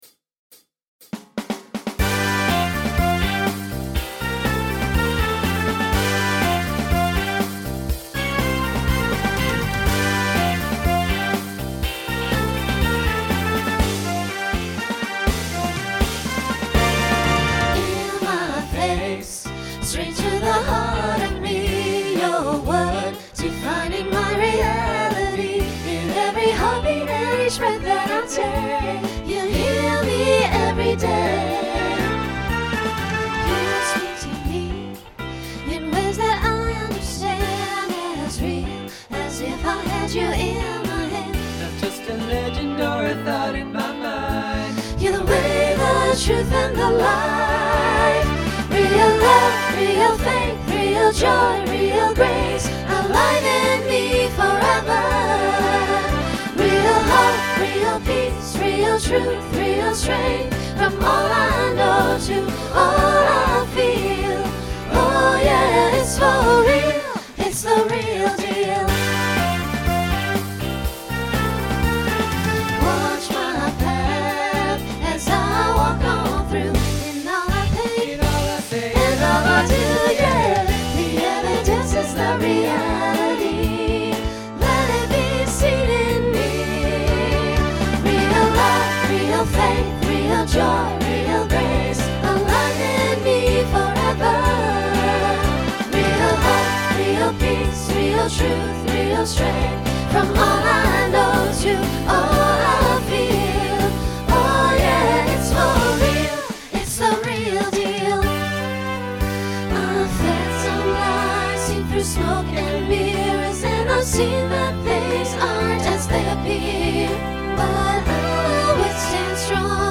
Voicing SATB Instrumental combo Genre Pop/Dance , Rock
Mid-tempo